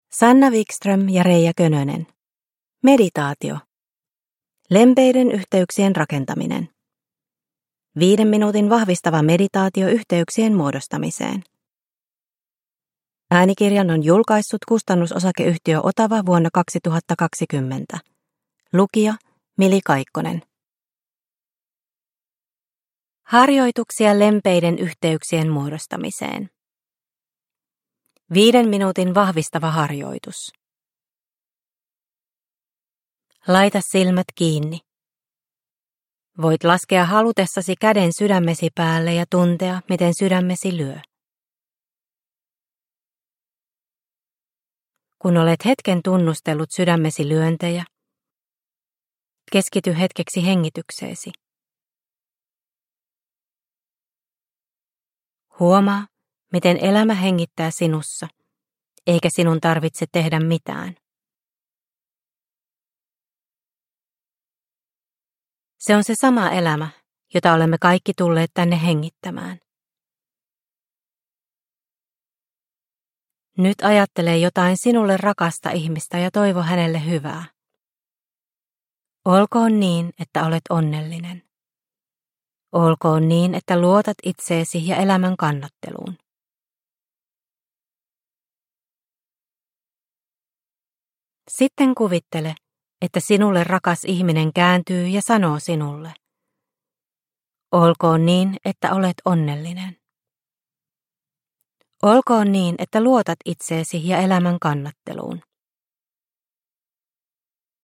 Meditaatio - Lempeiden yhteyksien rakentaminen – Ljudbok – Laddas ner